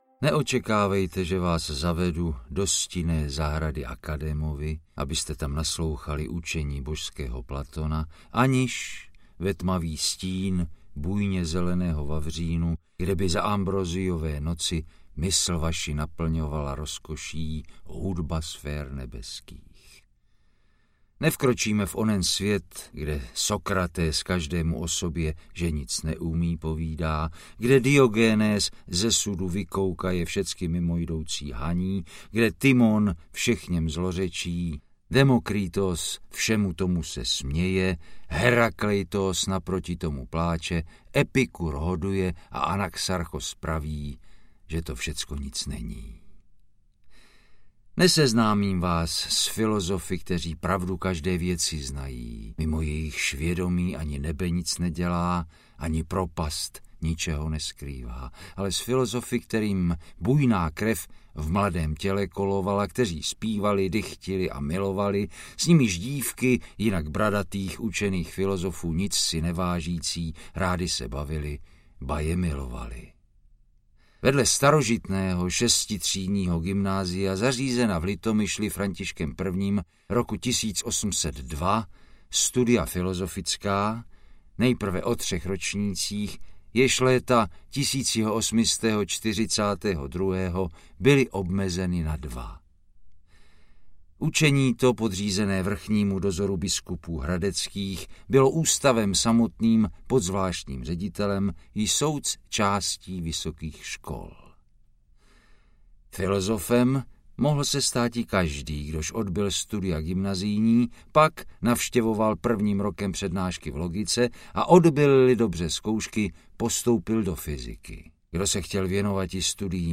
Filosofská historie audiokniha
Ukázka z knihy
• InterpretJiří Štědroň